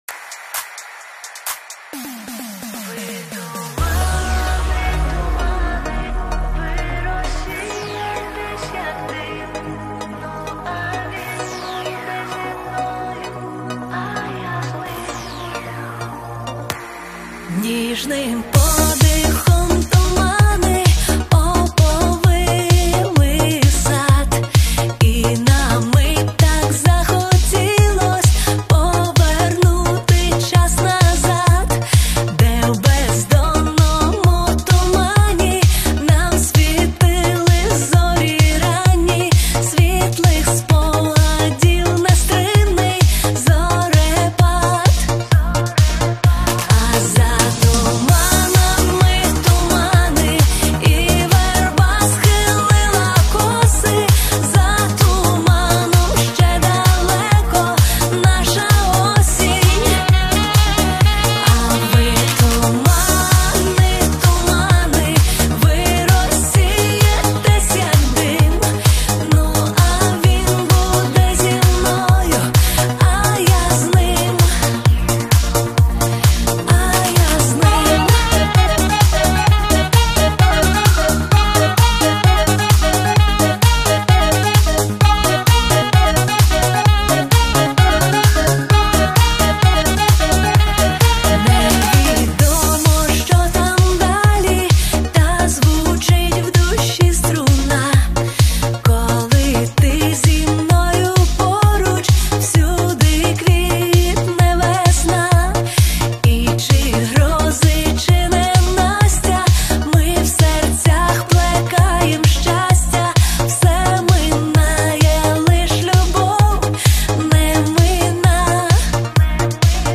Збірка найкращих танцювальних хітів